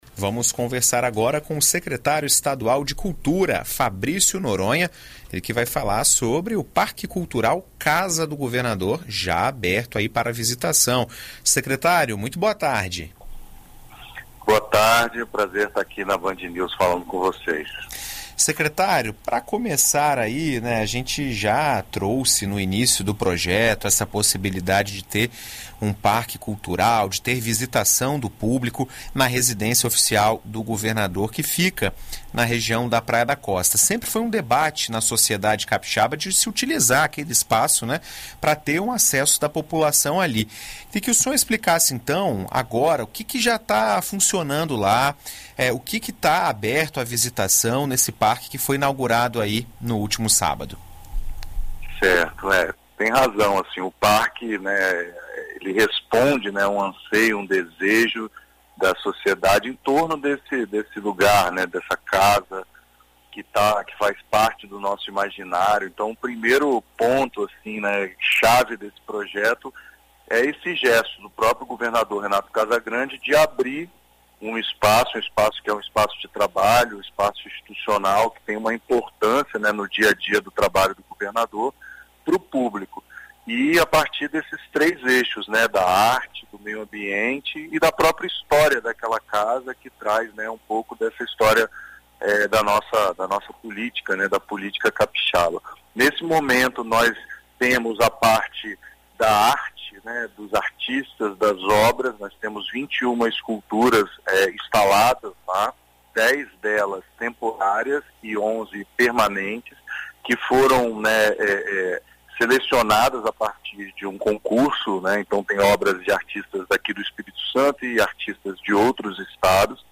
Em entrevista à BandNews FM Espírito Santo nesta terça-feira (31), o secretário estadual de cultura, Fabrício Noronha, comenta os detalhes do espaço e explica como agendar a visita ao local.